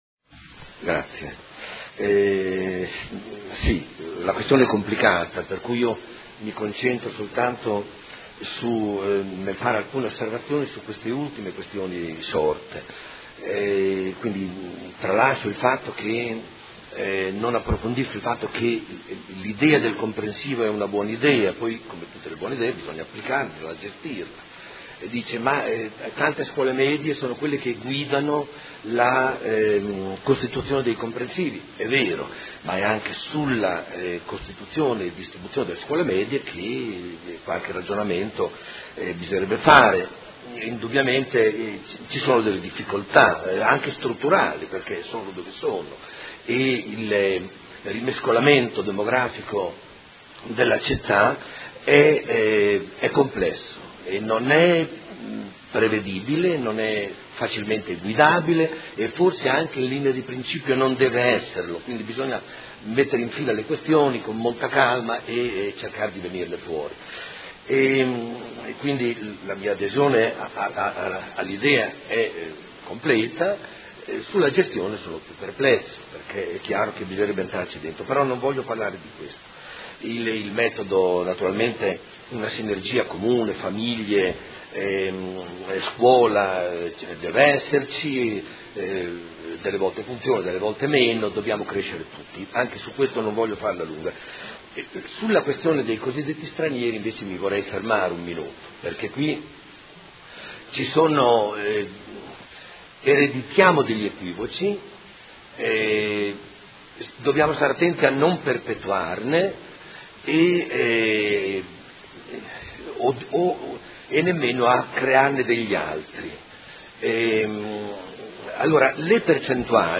Seduta del 5/11/2015. Interrogazione del Consigliere Rocco (FaS) avente per oggetto: Ridimensionamento rete scolastica. Dibattito